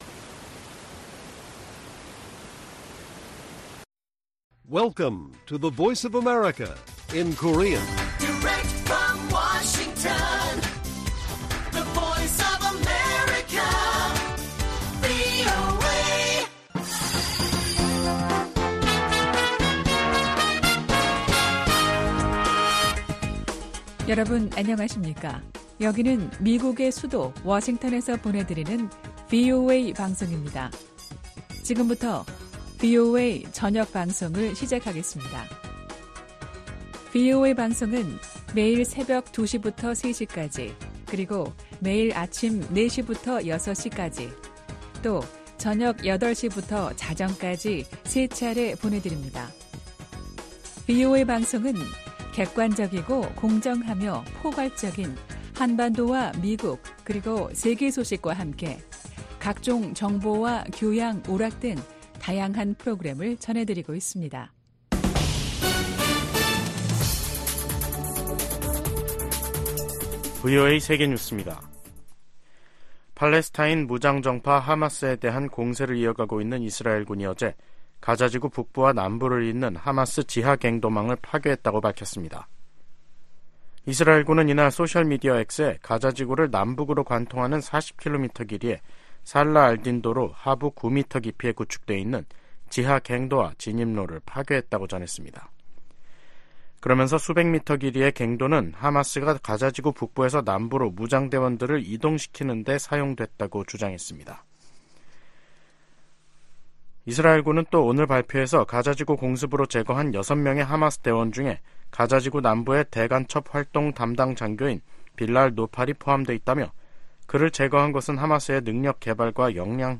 VOA 한국어 간판 뉴스 프로그램 '뉴스 투데이', 2024년 1월 17일 1부 방송입니다. 블라디미르 푸틴 러시아 대통령이 모스코바에서 최선희 북한 외무상을 만났습니다.